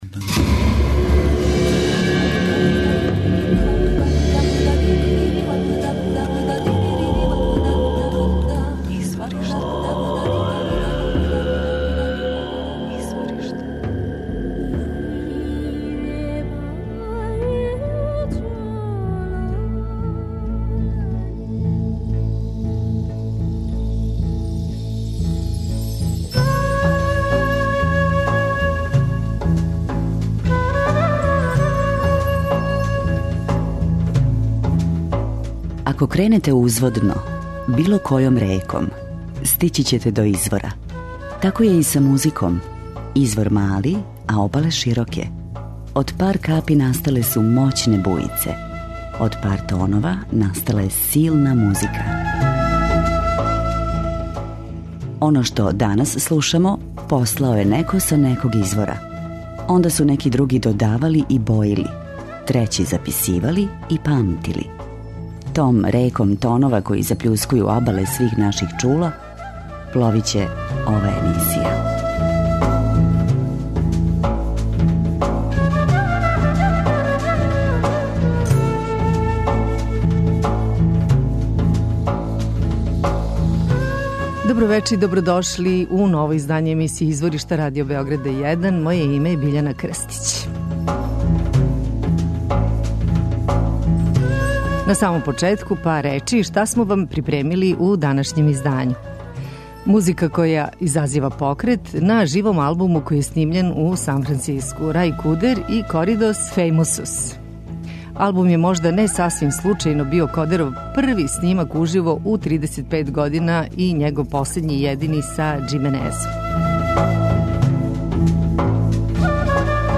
У данашњем издању музика која изазива покрет
први снимак уживо у 35 година
sa Tex-Mex accordionist